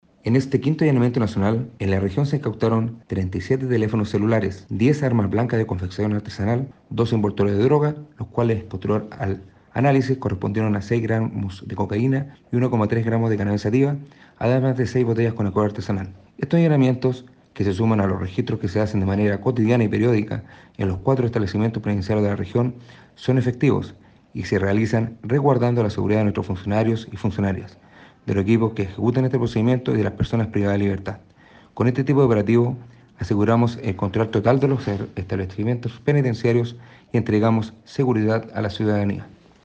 Esto como parte del Plan de seguridad penitenciaria contra el crimen organizado impulsado por el Ministerio de Justicia y Derechos Humanos desde junio de 2022, en el que en esta ocasión se requisaron celulares, droga y armas blancas, detalle que entregó el director regional de Gendarmería en Los Lagos, teniente coronel Gustavo Flores Abarca.